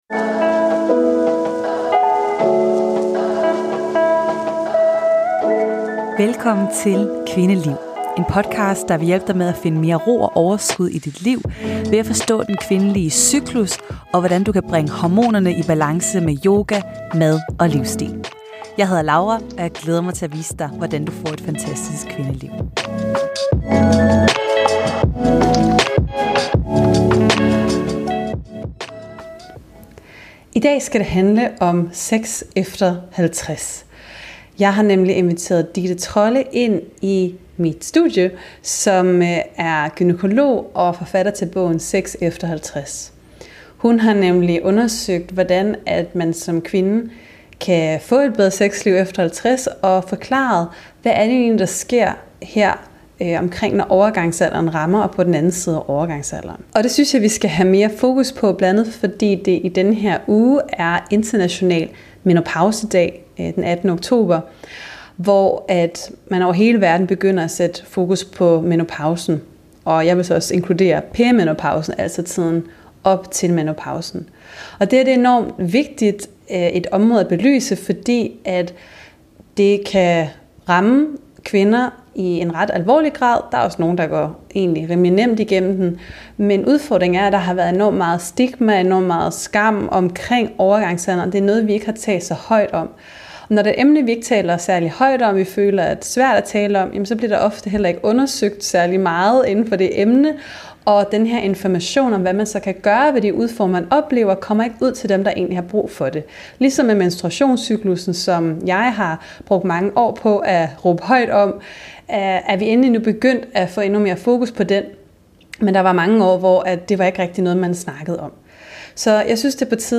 Vi belyser forskellene i sexlyst mellem mænd og kvinder, og hvad der sker for kvinder i overgangsalderen. Samtalen bevæger sig derefter ind på området for kommunikation i seksuelle forhold, de rigtige rammer for disse diskussioner og forståelse af ændringer i sexlyst under og efter graviditet.